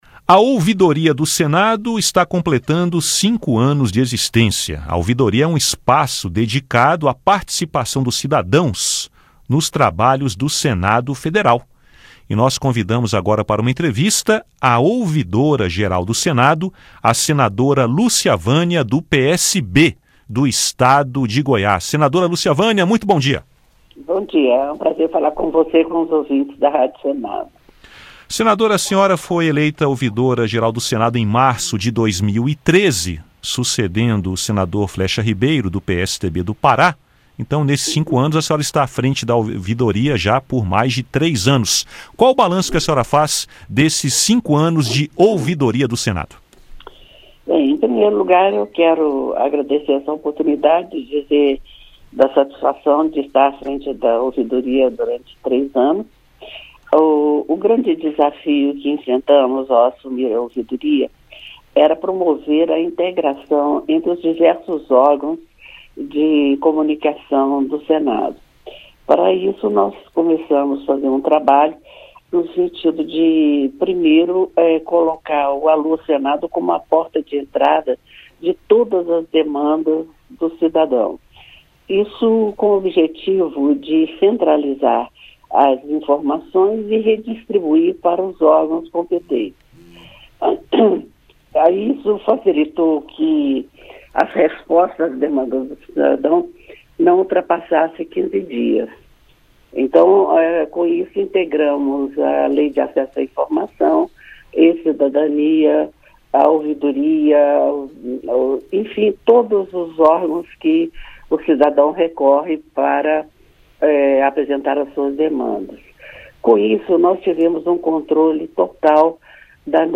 A Ouvidoria do Senado recebeu mais de 35 mil mensagens de cidadãos no primeiro semestre de 2016, com destaque para manifestações sobre o processo de impeachment e a crise política. Foi o que afirmou a ouvidora-geral, senadora Lúcia Vânia (PSB-GO), numa entrevista sobre os cinco anos da Ouvidoria.